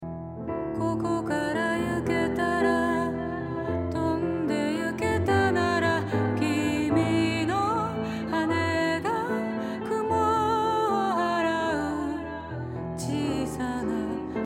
ディレイ+リバーブ
ディレイの「ボーカルが前に存在する」雰囲気とリバーブの「大きなスペース」が合わさって広大なスペースを意識させつつもボーカルがスペースに埋もれずに際立っていますね。